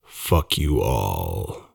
all american english fuck male spoken swear voice sound effect free sound royalty free Memes